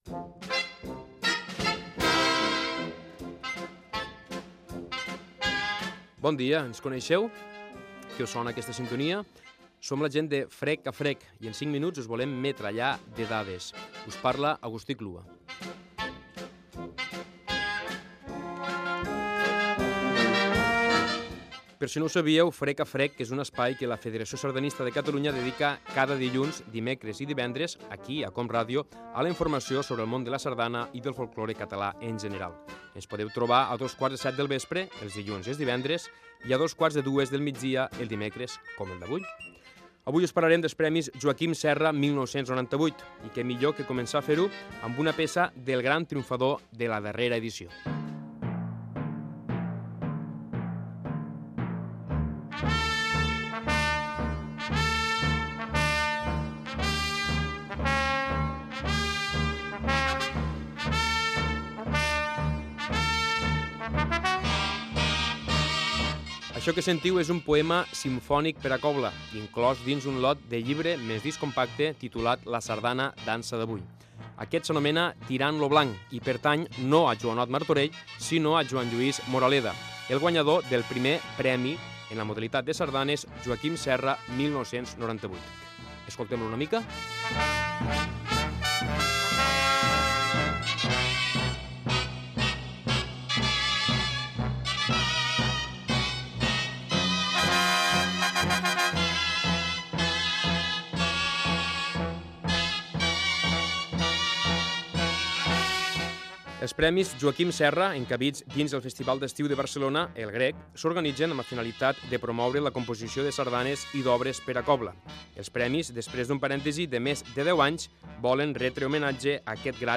Presentació i informació dels Premis Joaquim Serra 1998 amb algun tema musical